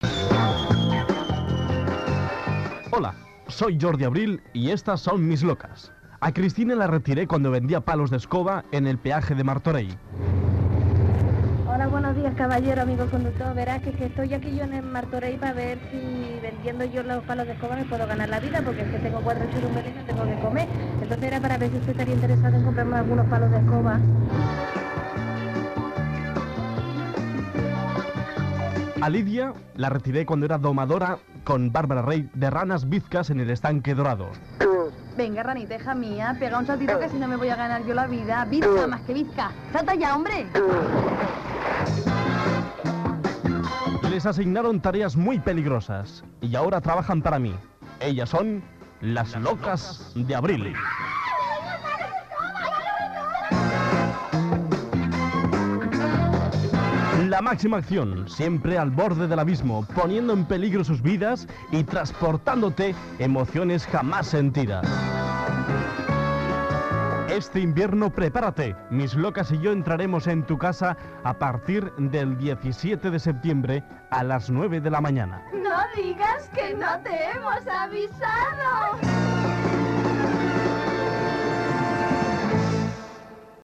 Promoció, anunciant la data d'inici del programa
Entreteniment